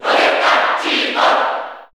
Category: Crowd cheers (SSBU) You cannot overwrite this file.
Rosalina_&_Luma_Cheer_Japanese_SSB4_SSBU.ogg